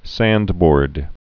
(săndbôrd)